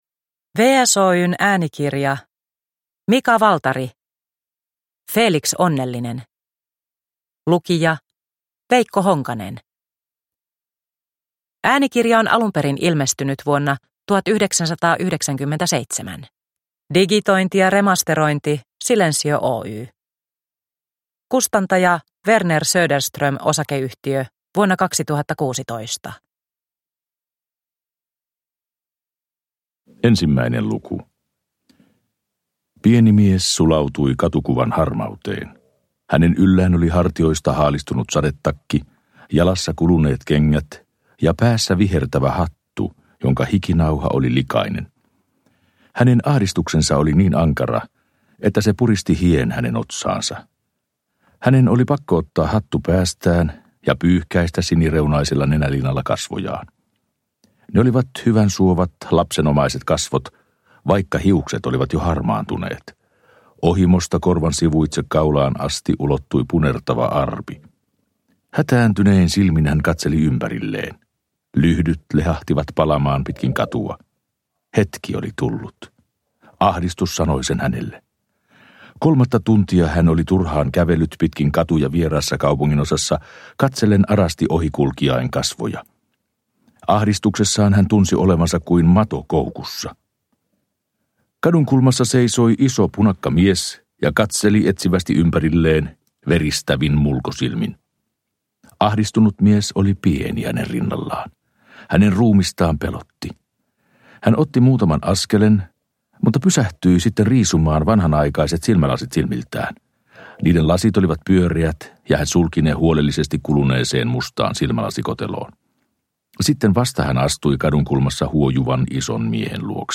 Feliks onnellinen – Ljudbok – Laddas ner